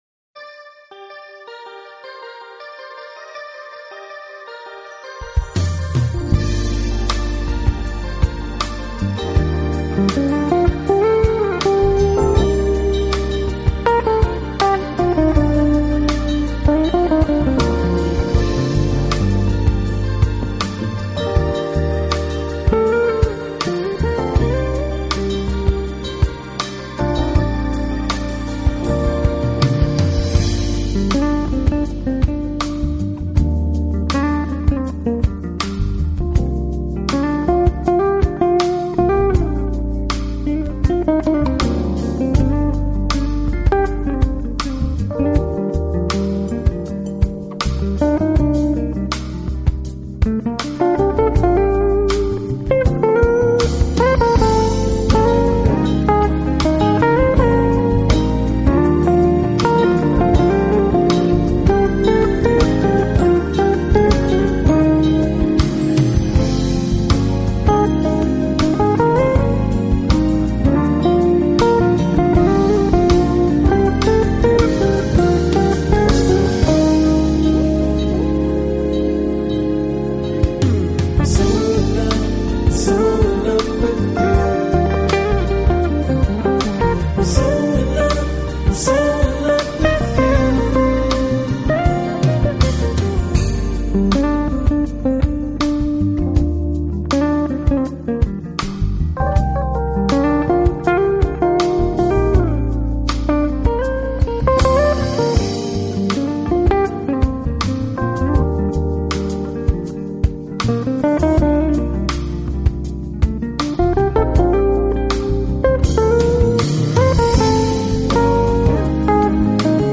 【爵士吉他】
一套精彩的爵士吉他合辑，荟萃了当今众多艺术名家，集爵士吉他之精华。